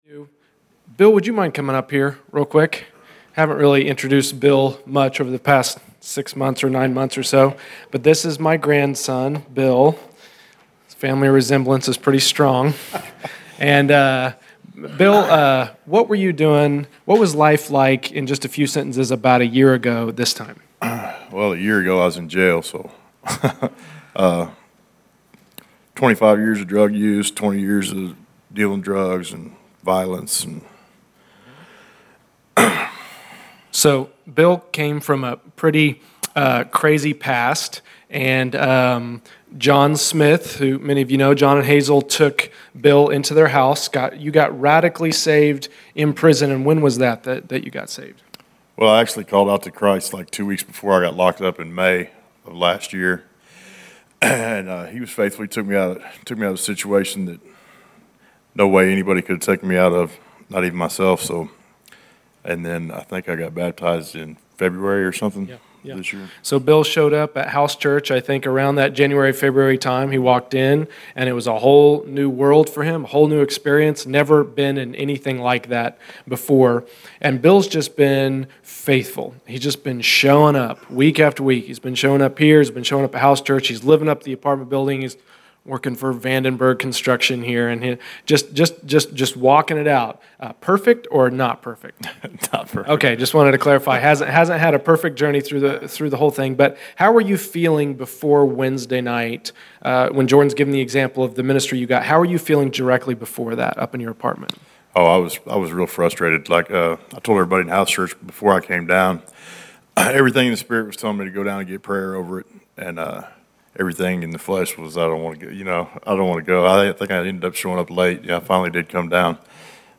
Testimonies      |      Location: El Dorado